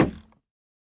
Minecraft Version Minecraft Version snapshot Latest Release | Latest Snapshot snapshot / assets / minecraft / sounds / block / bamboo_wood_hanging_sign / step1.ogg Compare With Compare With Latest Release | Latest Snapshot
step1.ogg